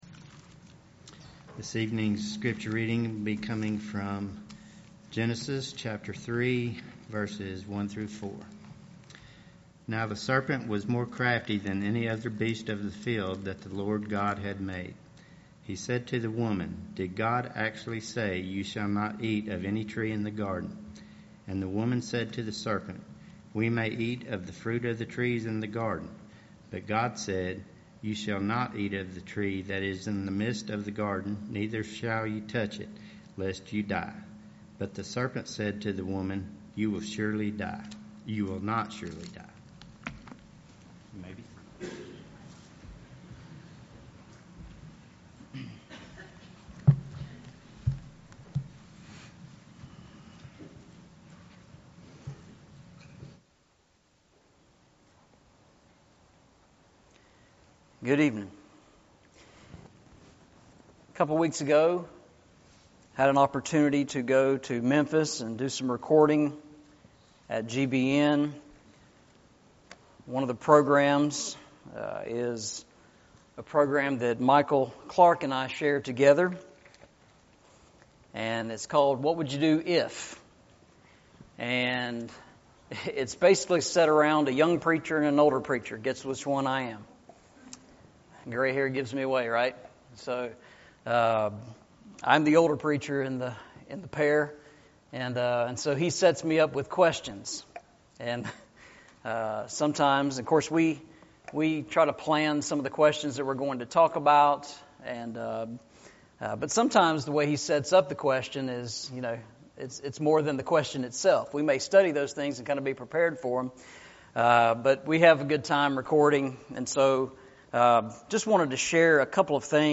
Genesis 3:1-4 Service Type: Sunday Evening « The Practice of the Church